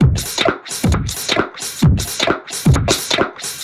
Index of /musicradar/uk-garage-samples/132bpm Lines n Loops/Beats
GA_BeatDSweepz132-02.wav